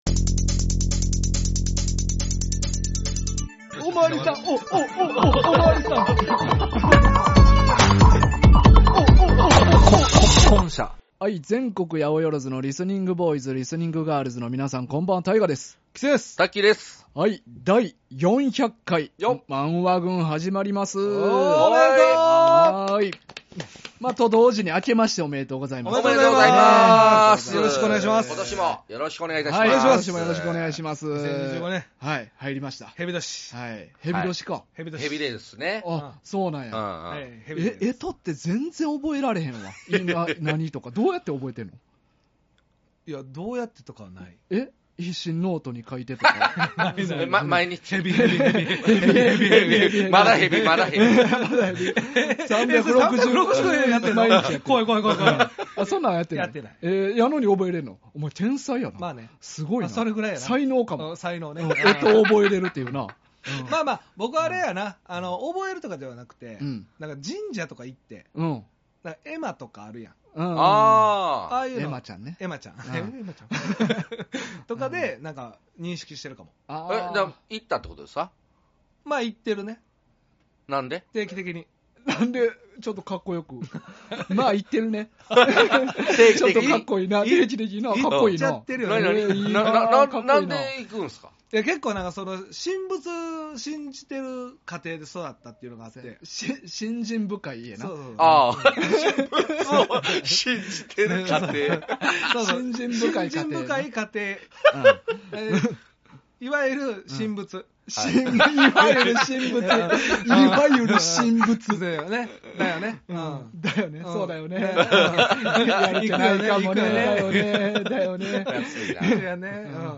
新年早々元気にしゃべってます！